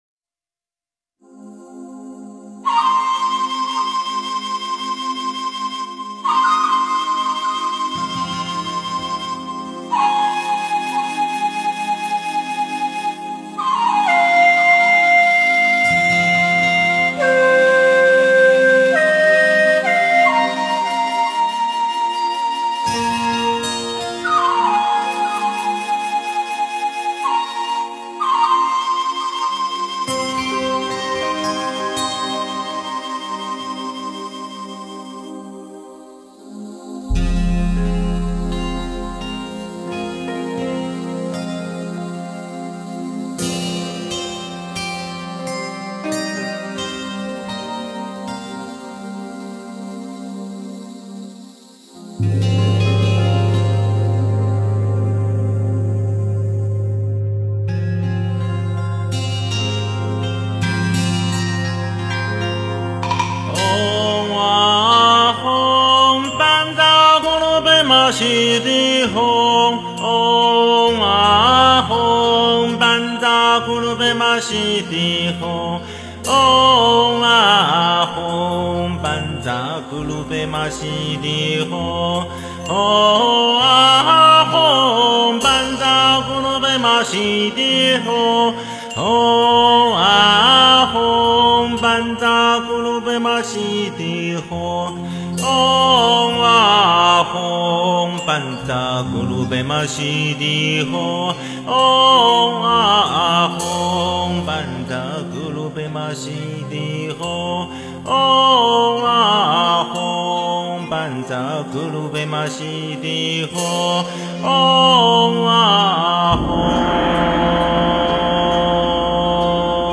诵经
佛音 诵经 佛教音乐 返回列表 上一篇： 观世音菩萨大悲心及大悲手印双运心咒 下一篇： 南无阿弥陀佛 相关文章 春江花月夜（古筝）--未知 春江花月夜（古筝）--未知...